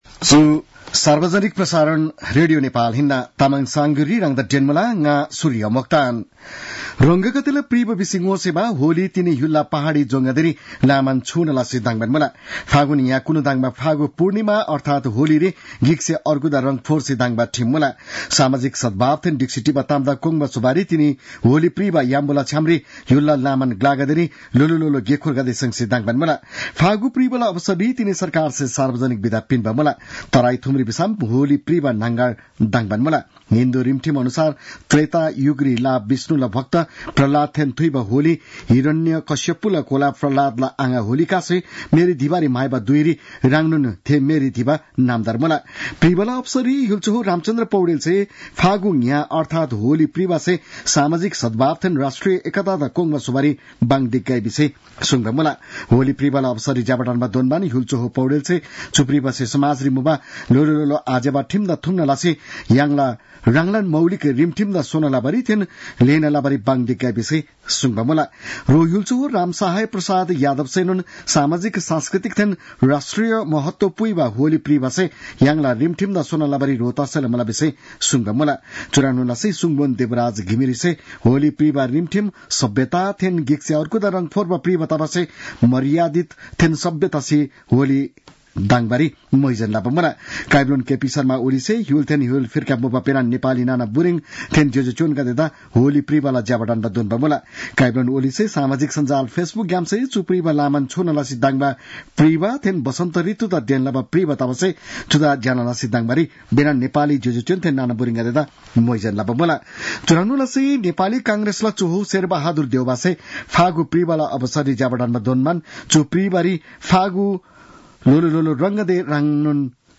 तामाङ भाषाको समाचार : ३० फागुन , २०८१